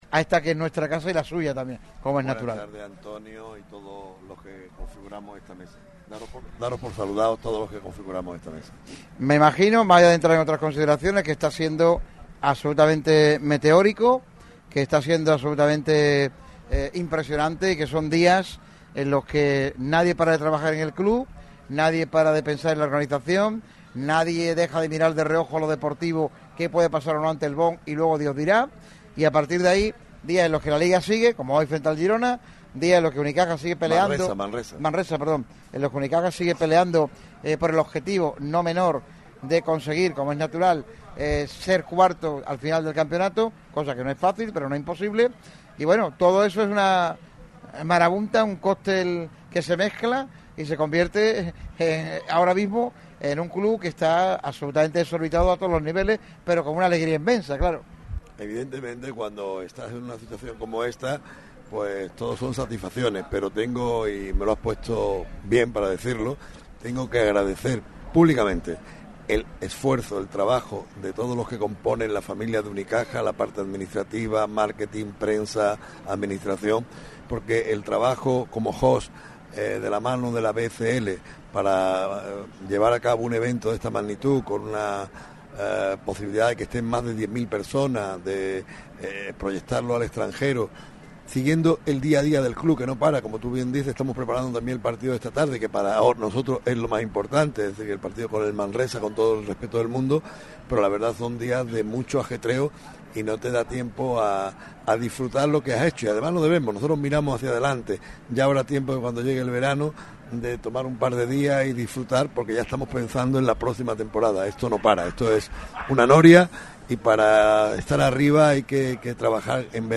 ha sido uno de los principales protagonistas de un programa especial celebrado por Radio MARCA Málaga en el Asador Iñaki. El motivo, celebrar la previa de la Final Four por la BCL y el décimo aniversario de uno de los restaurantes más conocidos de la ciudad.